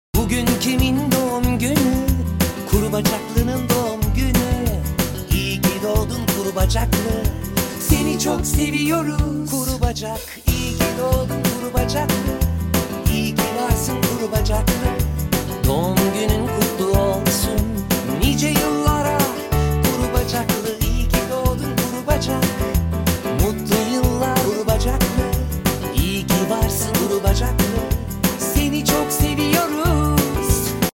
doğum günü şarkısı